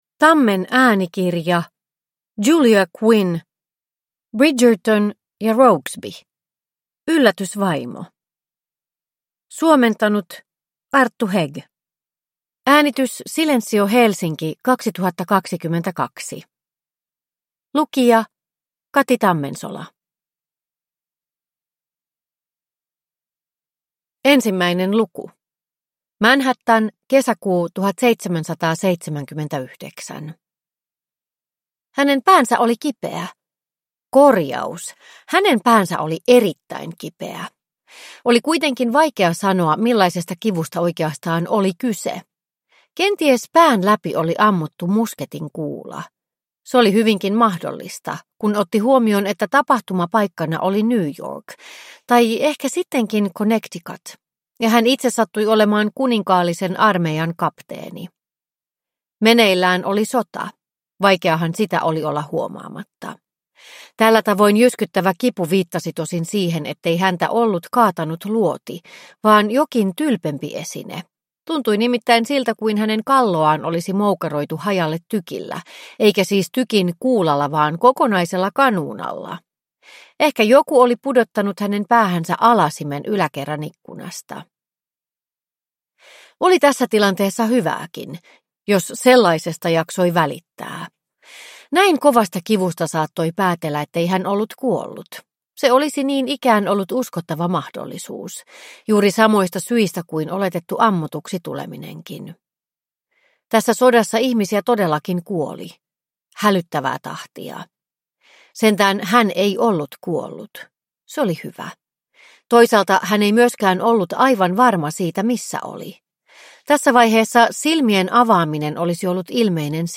Bridgerton & Rokesby: Yllätysvaimo – Ljudbok – Laddas ner